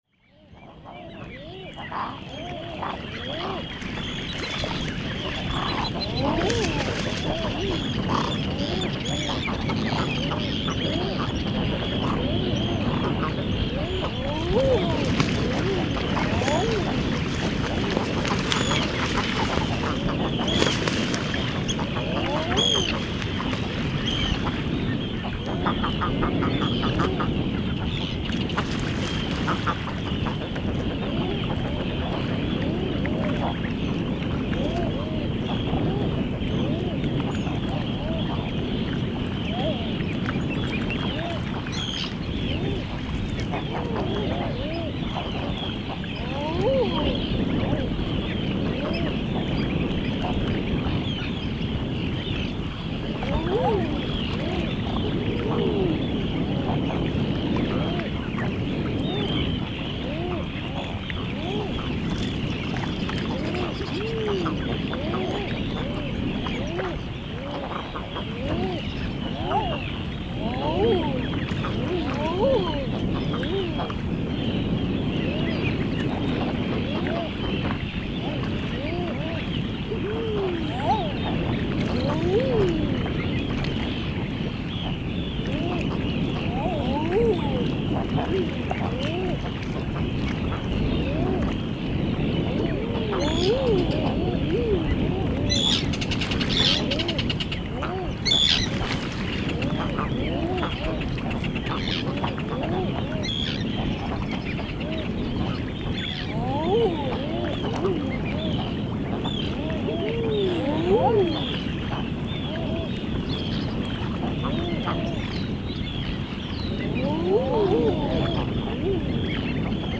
Þá eru æðarkollurnar orðnar léttari og farnar að svamla um með unga sína. Æðarblikarnir eru hinir íbyggnustu og ráða ráðum sínum en kollurnar eru fremur hryssingslegar.
fjaran_vi_grottu-01.mp3